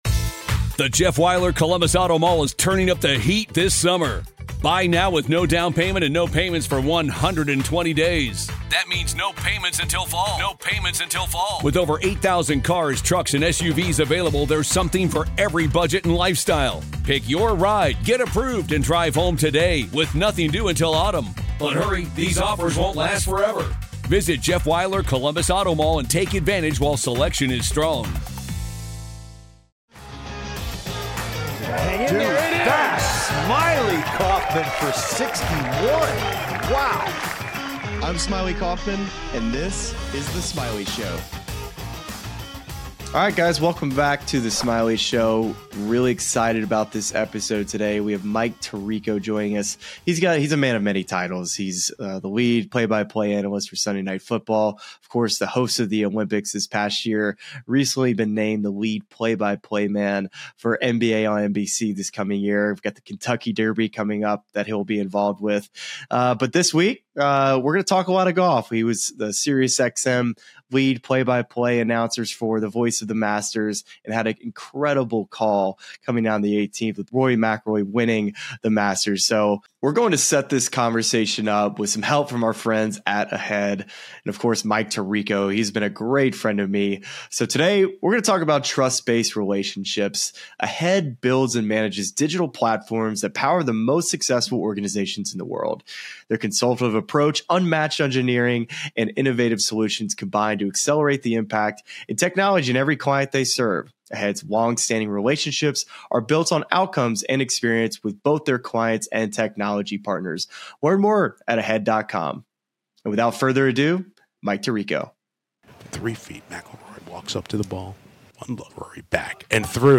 Mike Tirico Interview: Calling Rory's Grand Slam, Ryder Cup 2025 & more!